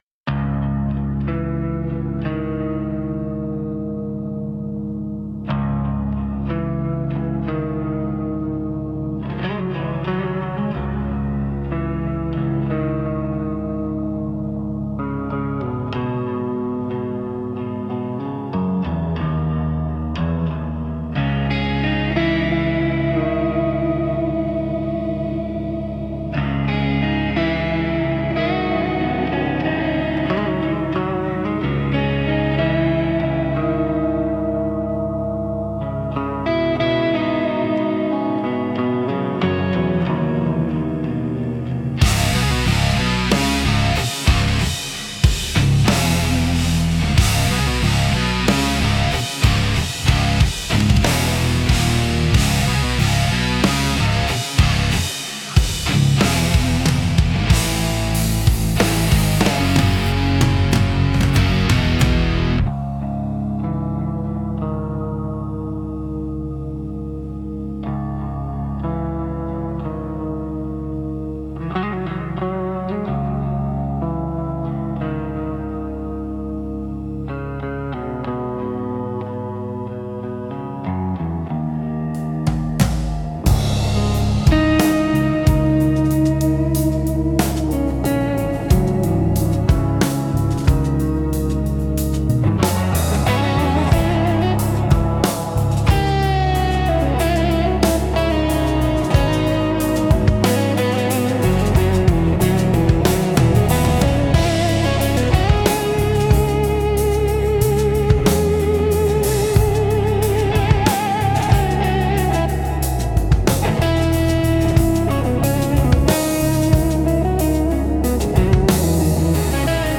Instrumental - Horizon Memory 4.13 - Grimnir Radio